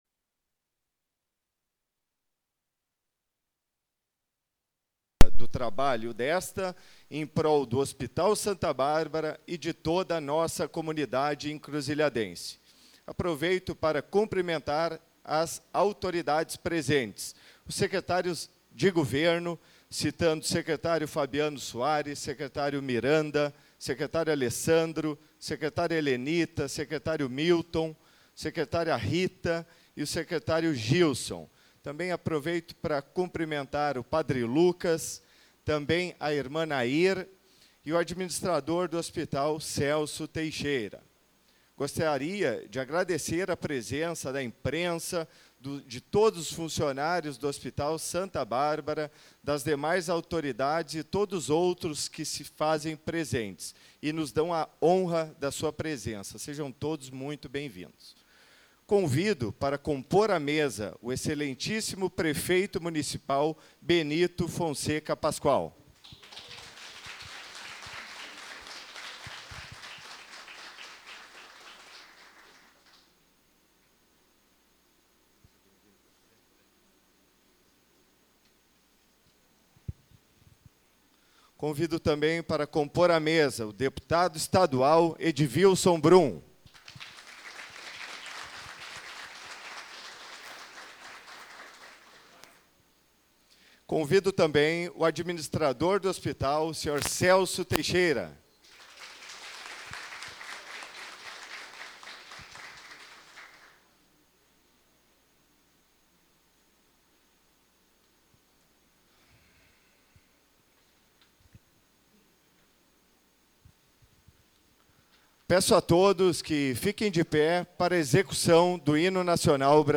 Sessão Solene 12.12.2024